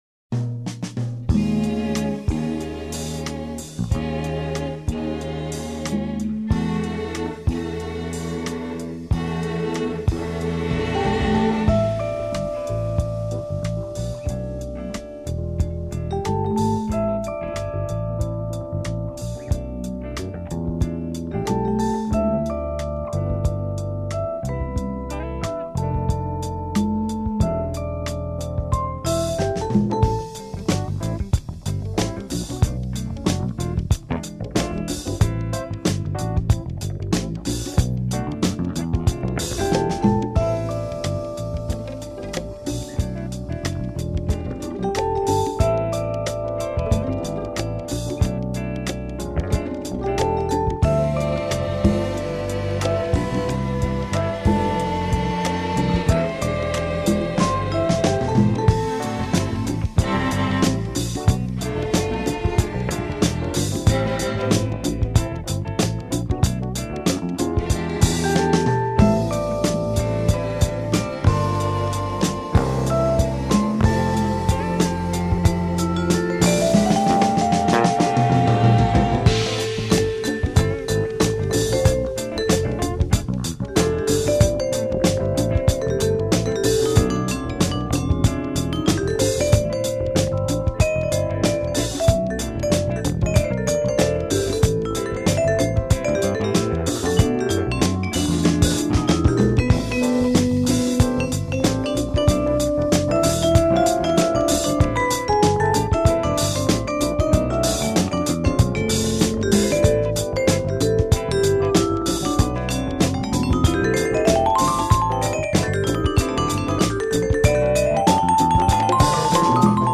Smooth Jazz